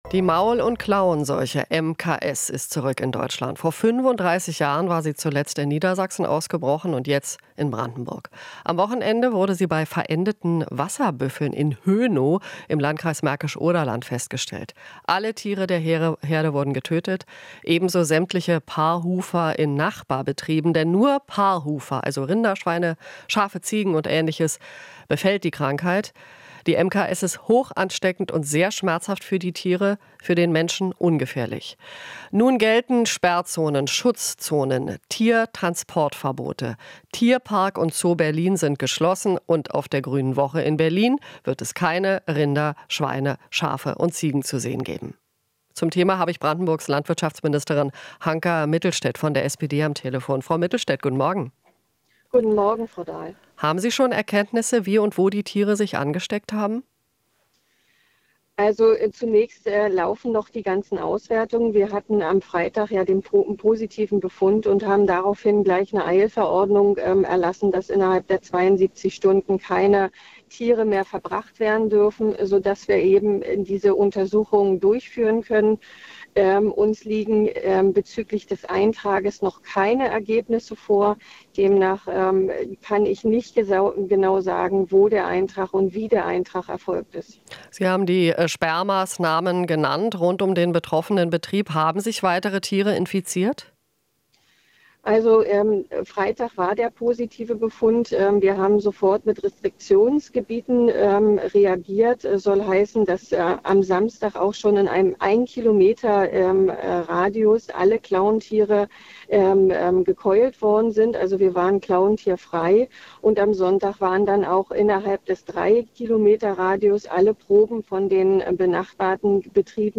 Interview - Maul- und Klauenseuche in Brandenburg: Keine Hinweise auf Ausbreitung
Die Maul- und Klauenseuche hat sich offenbar nicht weiter in Brandenburg ausgebreitet. Wie es weitergeht, wird nun besprochen, sagt Brandenburgs Landwirtschaftsministerin Hanka Mittelstädt (SPD).